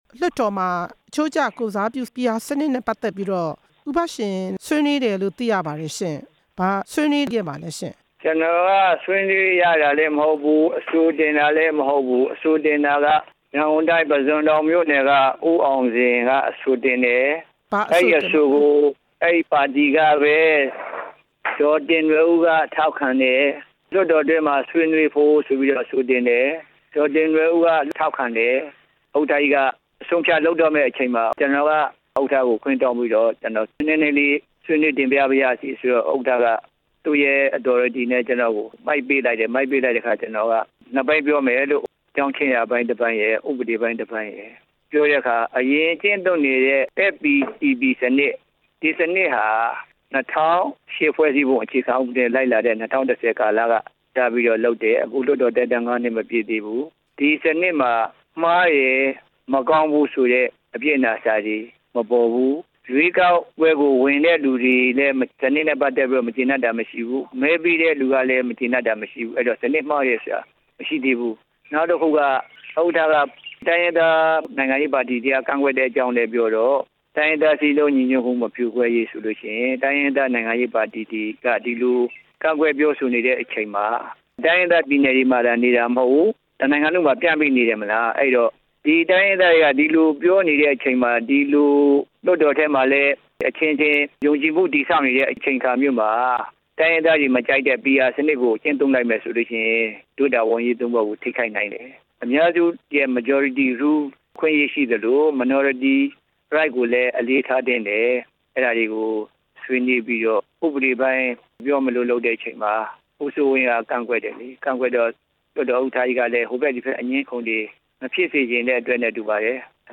ပြည်သူ့လွှတ်တော် ကိုယ်စားလှယ် ဦးဘရှင်နဲ့ မေးမြန်းချက် နားထောင်ရန်